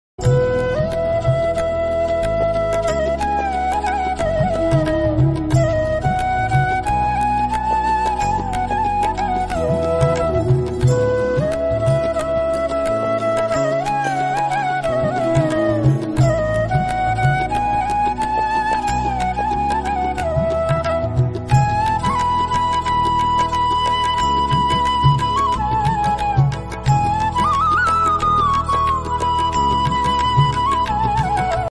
Instrumental Ringtone.